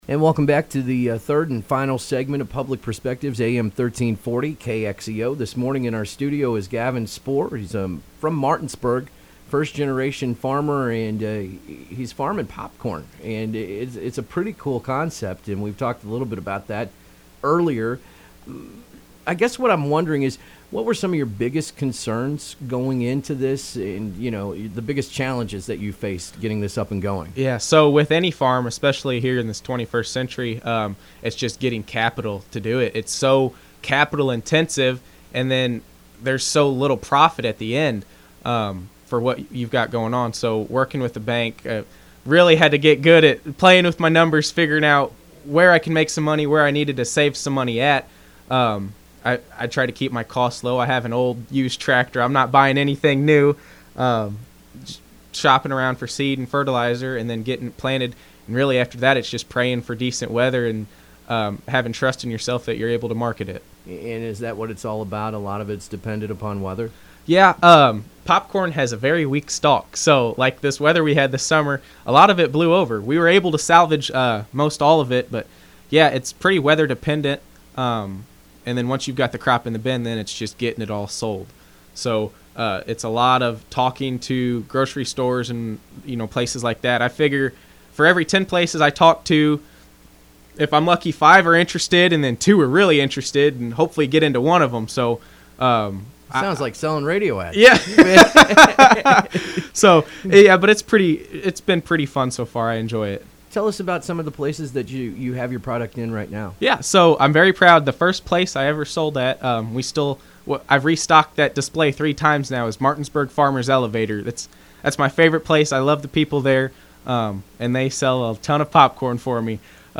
Complete Interview